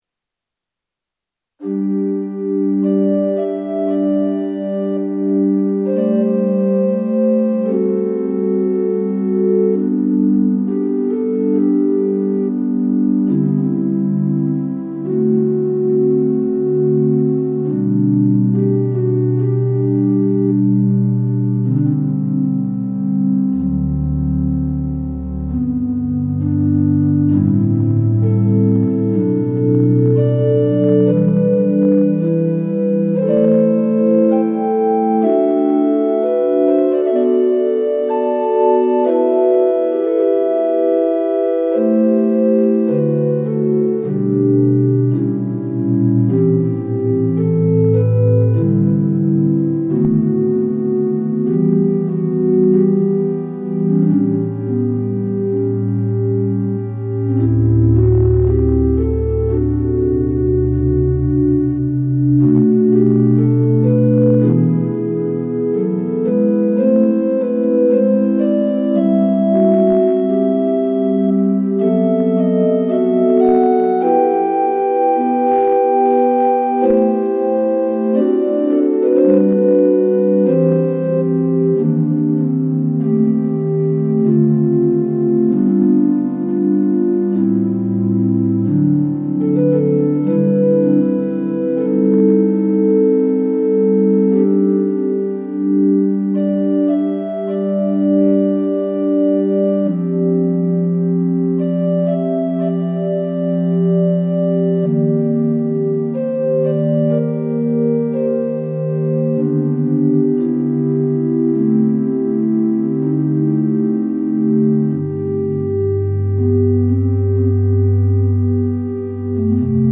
Organ Audio: Miscellaneous